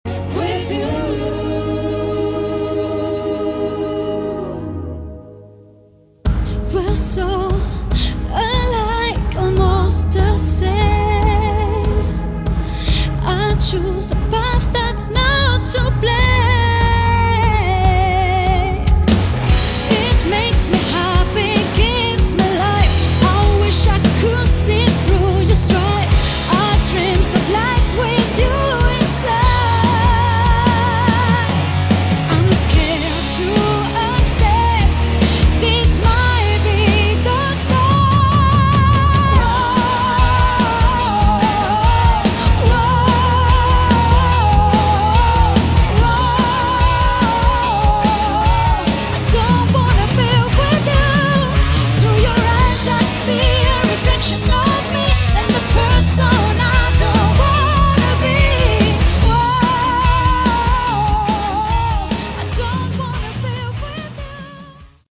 Category: Melodic Rock
vocals
guitar
drums
bass